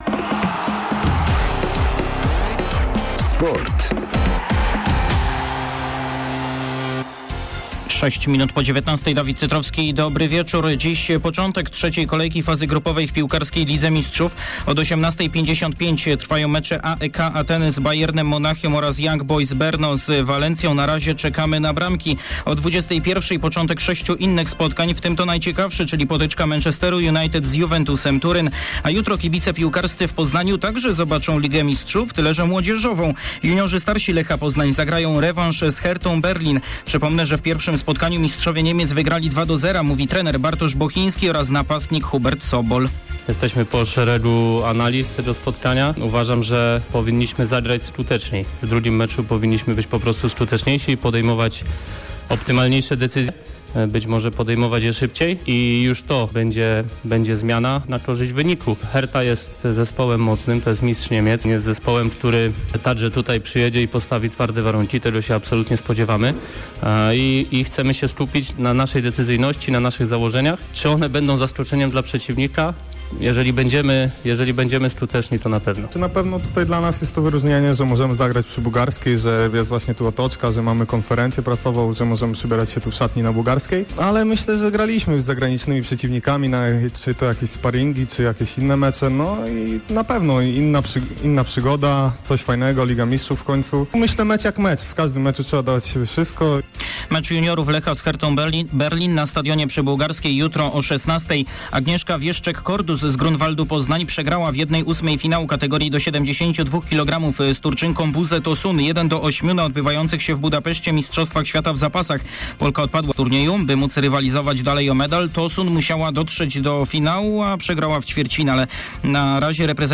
23.10. serwis sportowy godz. 19:05